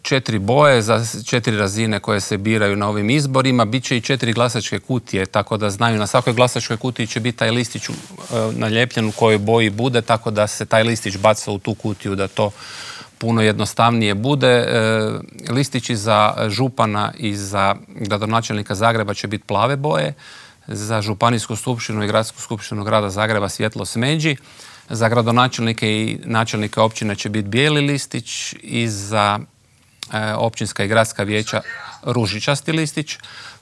O nadolazećim izborima, pravilima glasovanja, ali i o izbornoj šutnji razgovarali smo u Intervjuu tjedna Media servisa s članom Državnog izbornog povjerenstva Slavenom Hojskim.